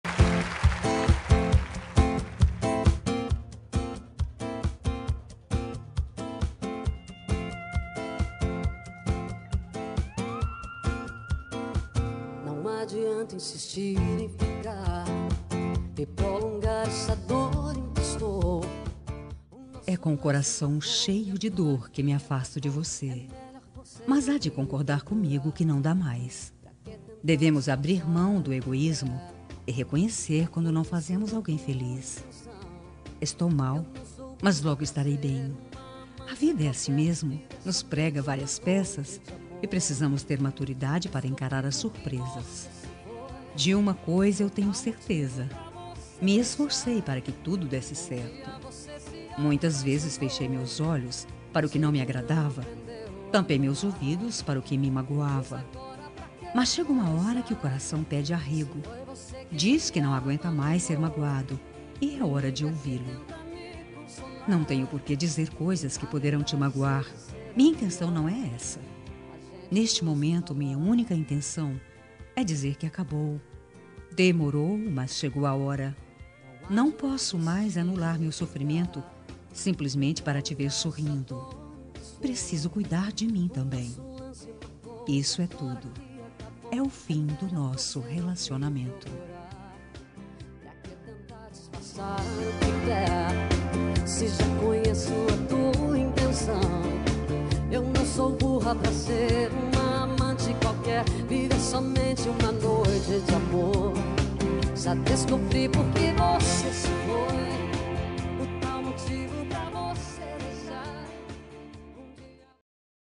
Término – Voz Feminina – Cód: 8661
termino-fem-8661.m4a